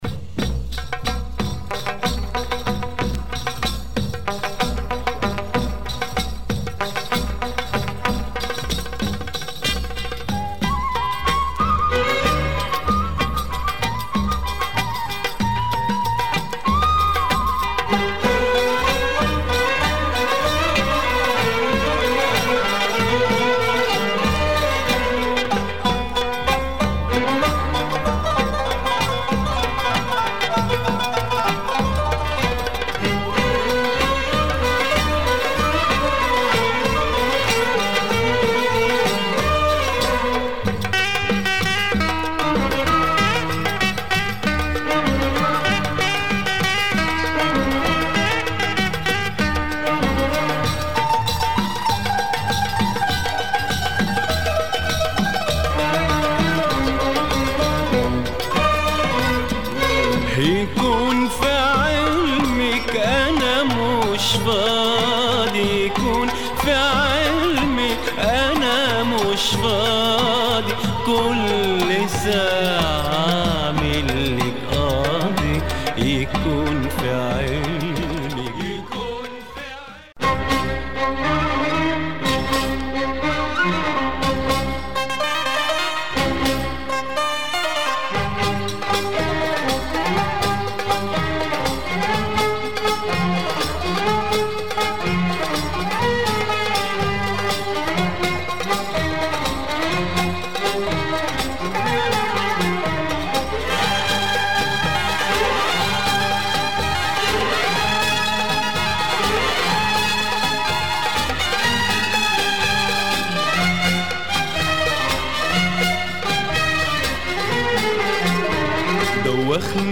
Lebanese singer and oud player
in studio and performing live
Some beautiful beats here.
oud